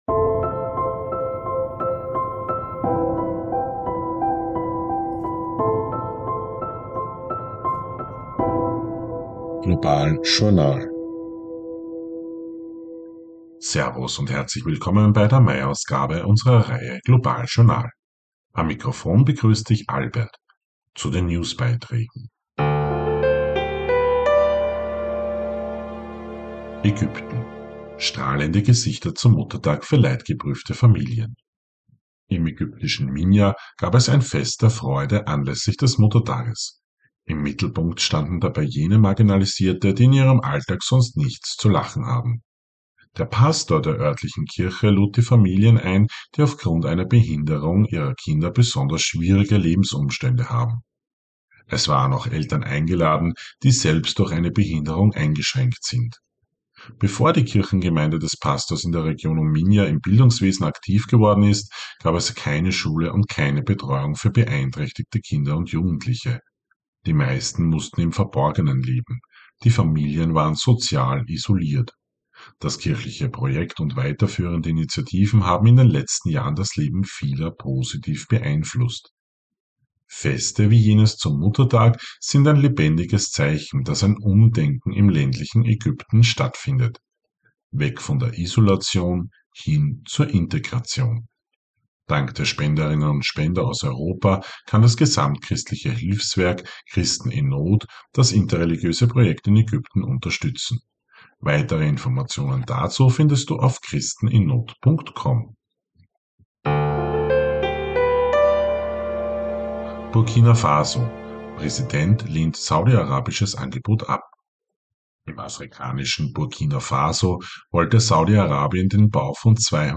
News Update Mai 2025